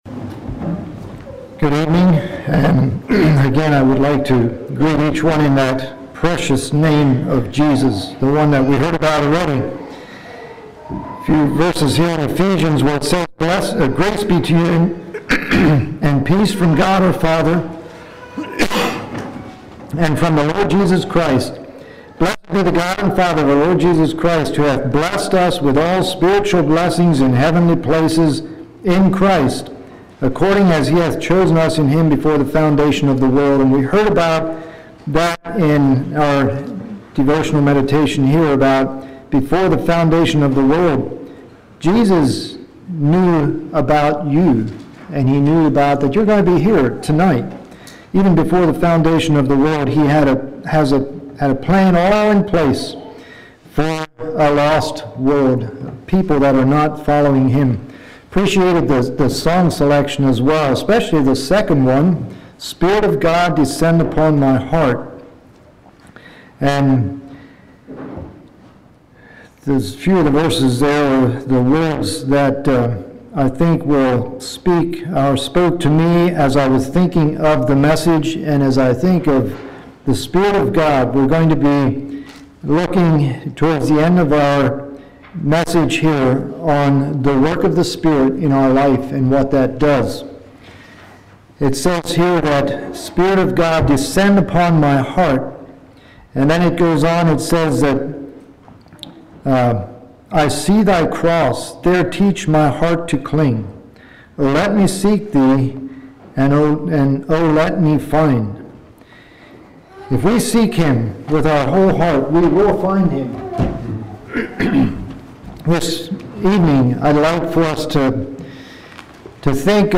Sermons
Ridge View | Tent Meetings 2023